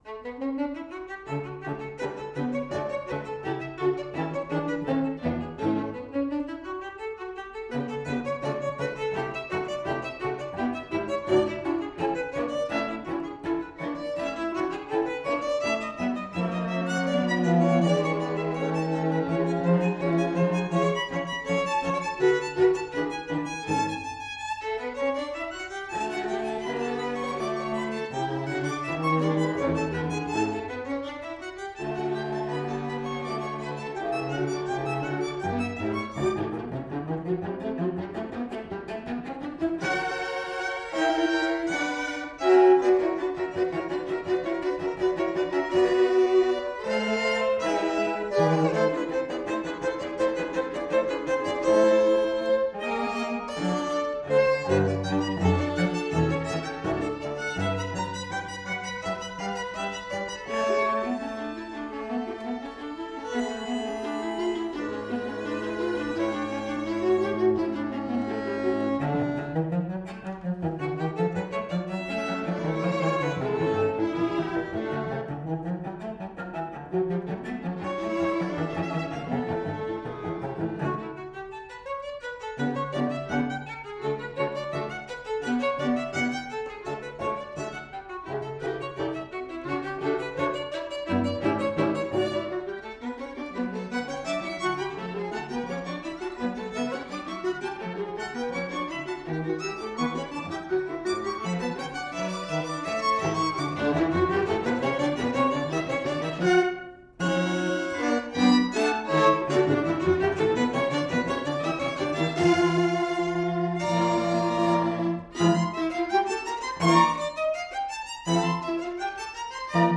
toe-tapping, lively and full of excitement